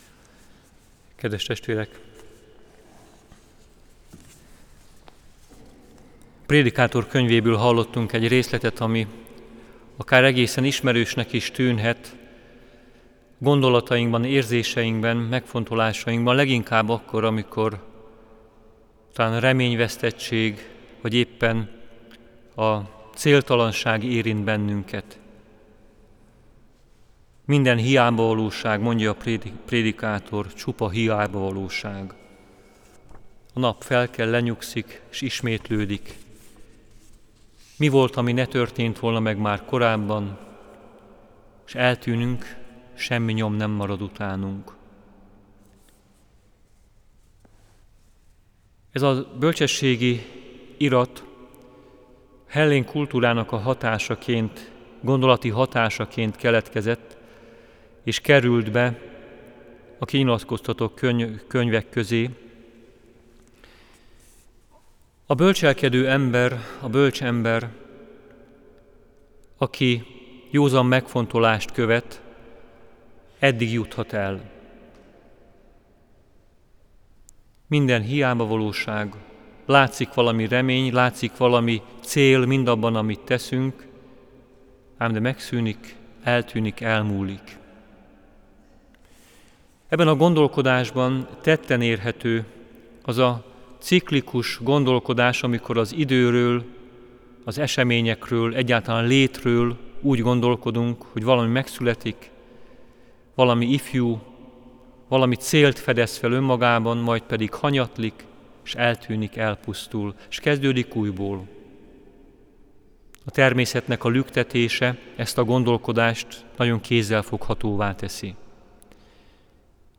A Pécsi Székesegyházban 2014. szeptember 25-én, 18.00 órakor kezdődő szentmisét követően az ősz folyamán tovább folytatódnak a felnőtt katekézisek a Dóm Kőtárban.